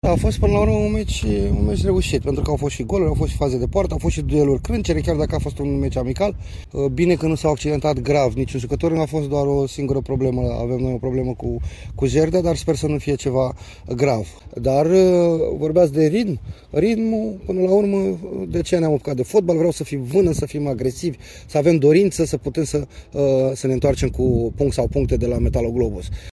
Flavius Stoican (CSM Reșița) e deja cu gândul la primul meci din play-off, cel de la Metaloglobus:
Stoican-despre-amicalul-CSMR-Corvinul-1.mp3